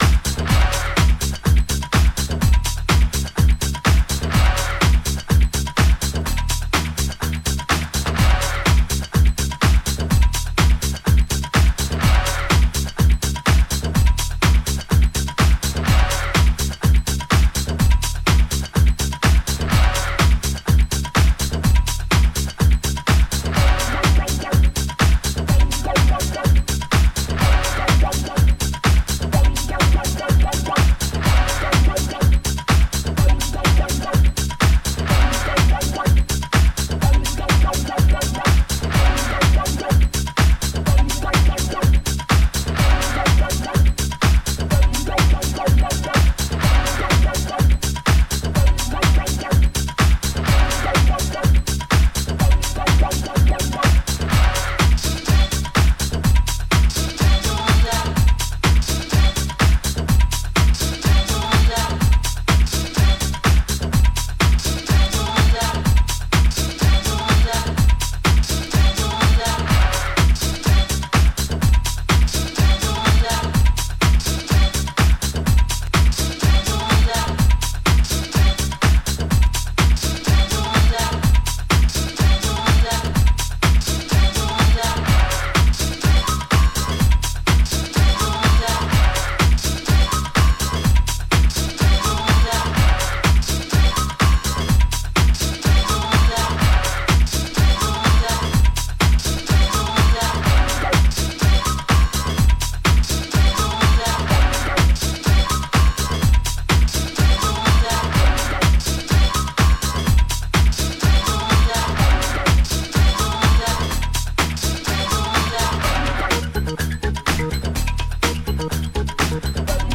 享楽的かつアンダーグラウンドな佇まいの全4曲